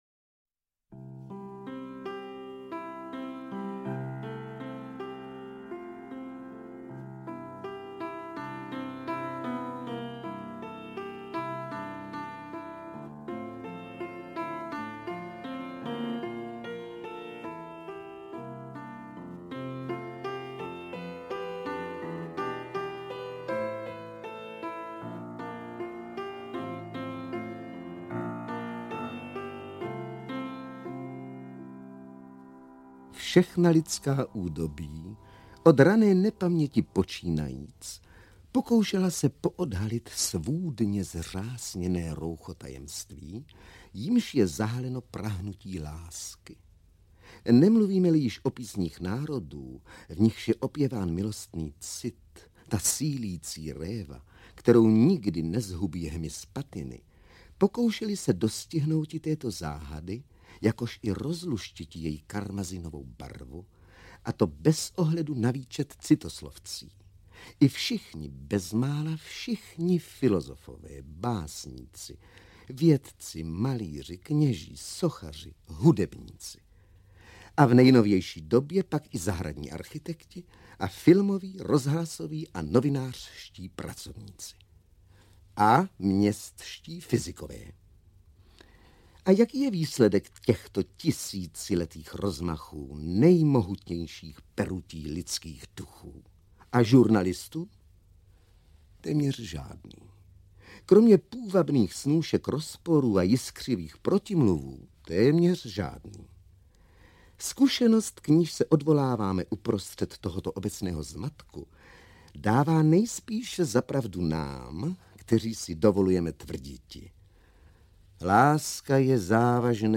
Supraphon vypravuje...8 (Konrád, Čapek, Horníček, Bezouška, Tomeček) - Karel Konrád - Audiokniha
• Čte: Miloš Kopecký